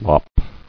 [lop]